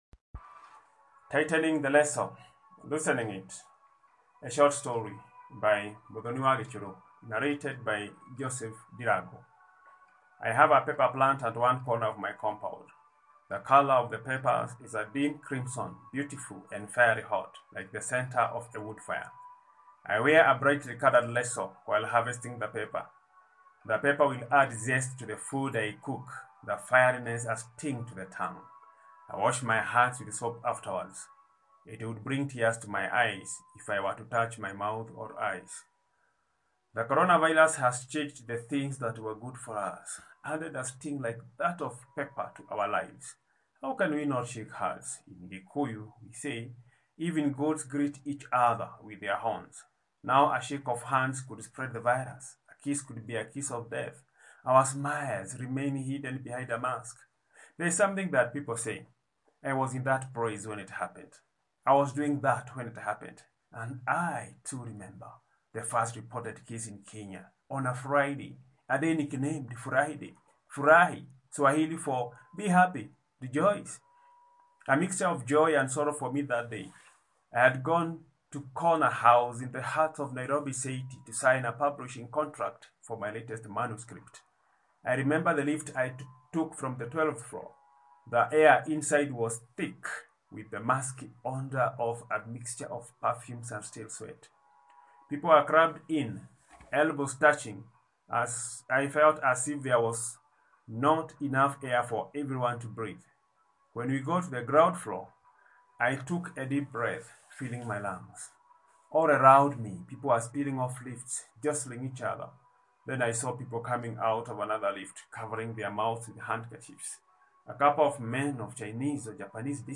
Écouter la lecture de l’œuvre